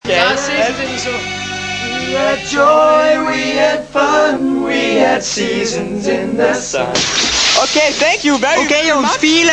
Accapella
The presenter is singing too!!!